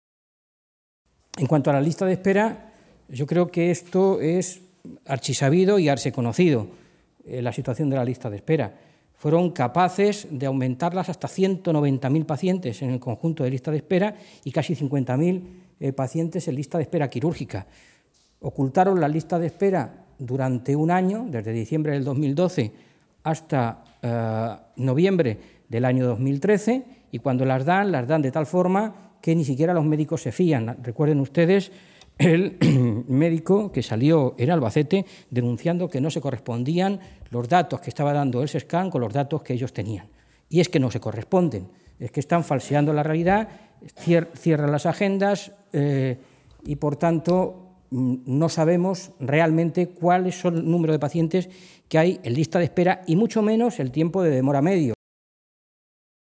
Fernando Mora, portavoz de Sanidad del Grupo Parlamentario Socialista
Cortes de audio de la rueda de prensa